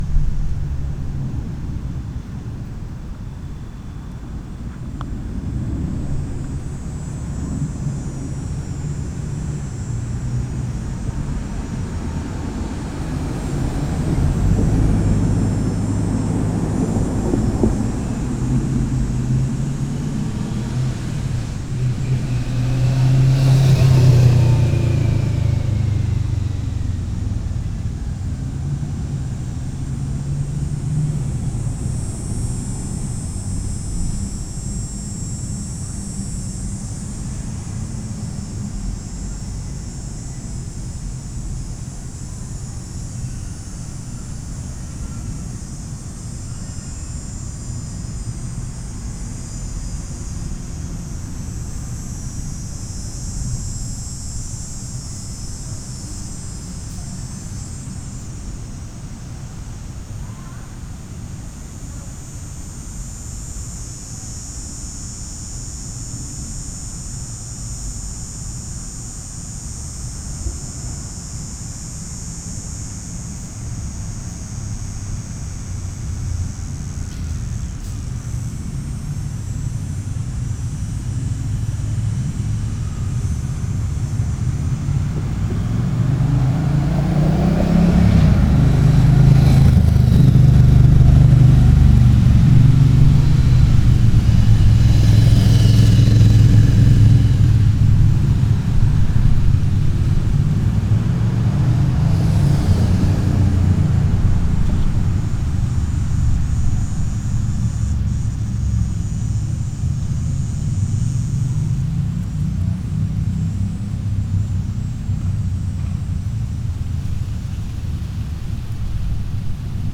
Field Recording
Park - Summer 2024 (Montreal, Quebec, Canada)